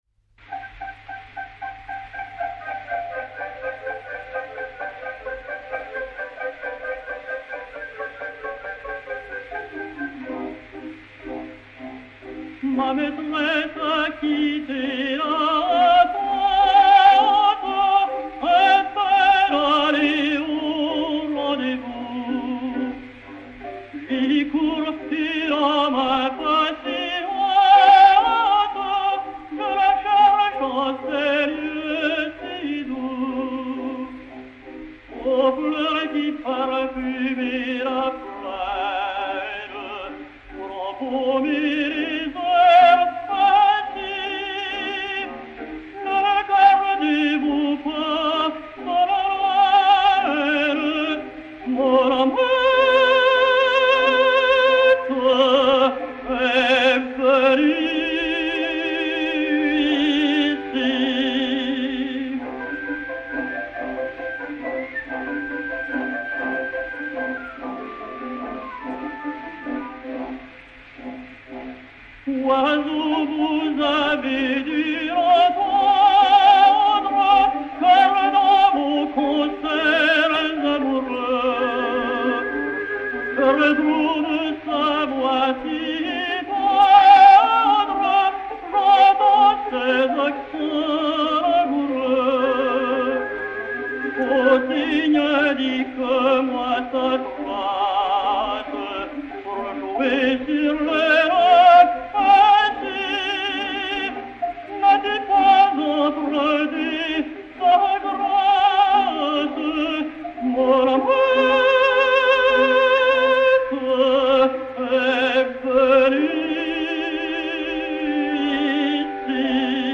Romance "Ma maîtresse a quitté la tente"
et Orchestre
Disque Pour Gramophone 3-32853, mat. 6224h, enr. en 1908